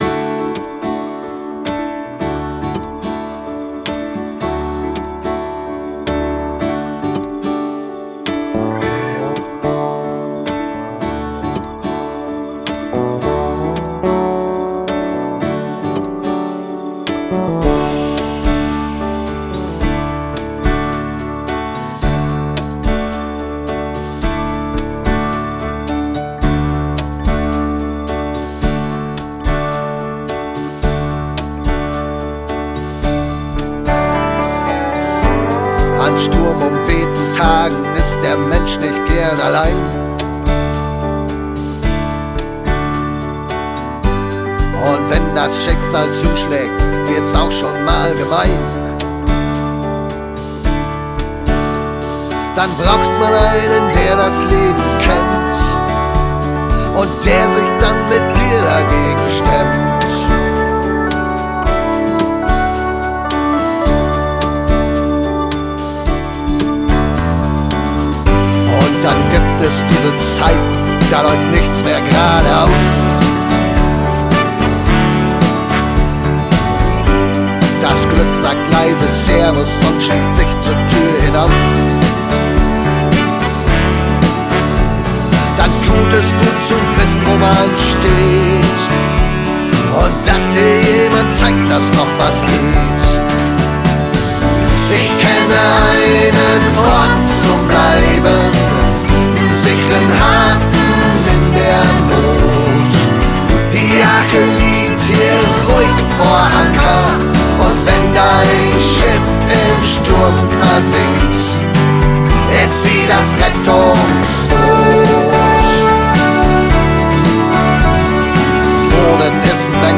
KLICK), der unter die Haut geht.